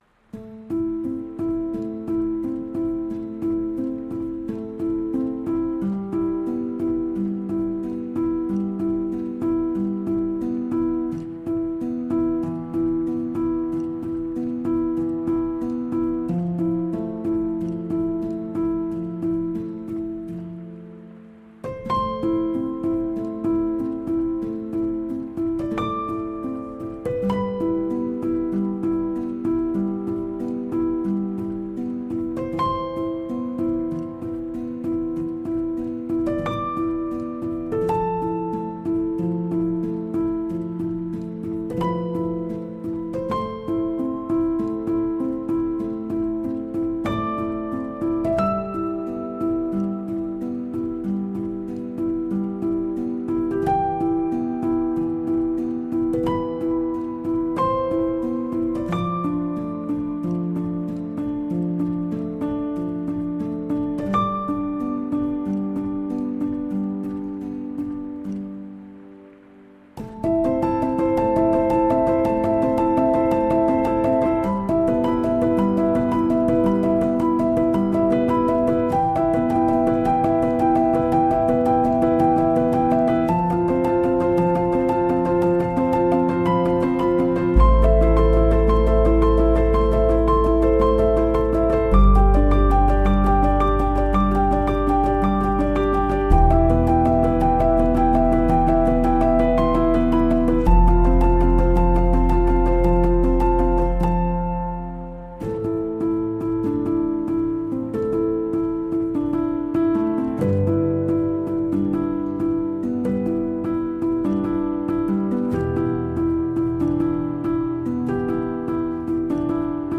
موسیقی زیر را روشن کرده و بعد مرحله به مرحله از دستورالعمل زیر پیروی کنید.